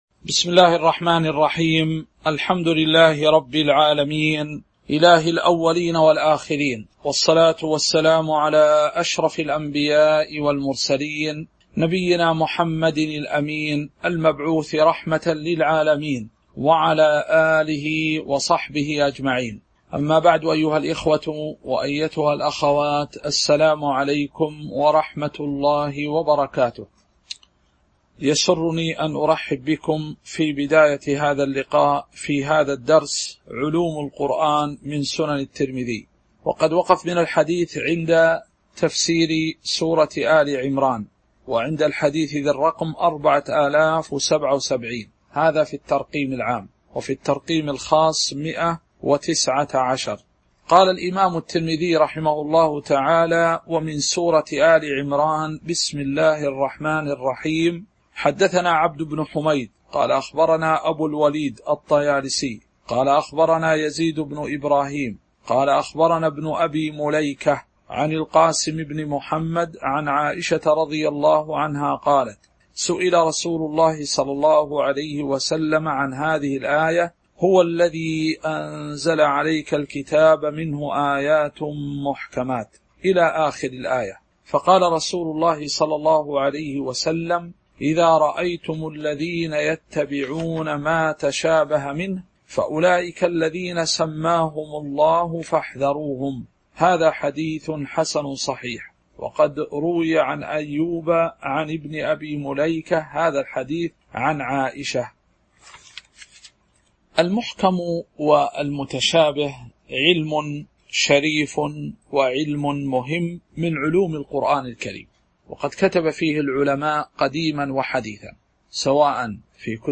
تاريخ النشر ٢٧ صفر ١٤٤٣ هـ المكان: المسجد النبوي الشيخ